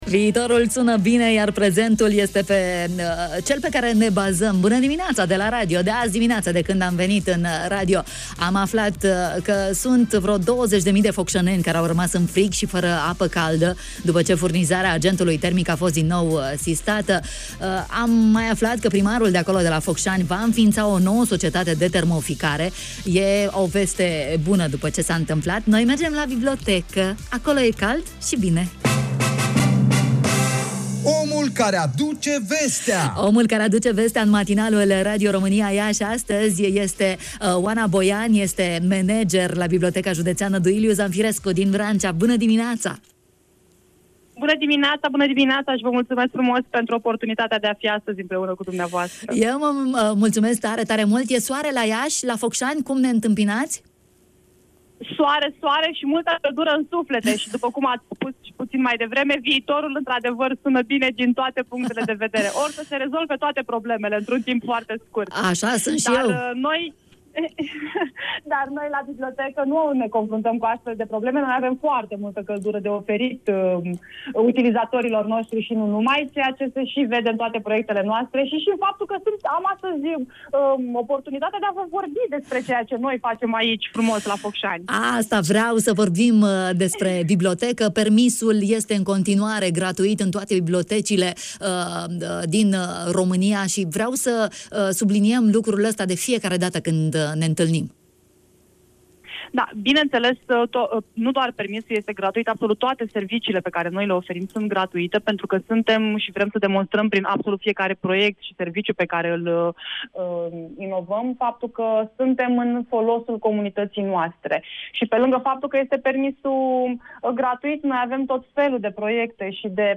în direct la matinalul Radio Iași